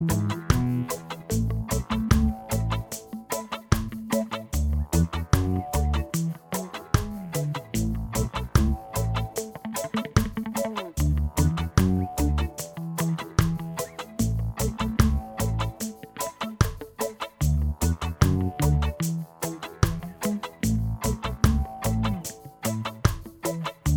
Minus Guitars Reggae 3:58 Buy £1.50